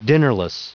Prononciation du mot dinnerless en anglais (fichier audio)
Prononciation du mot : dinnerless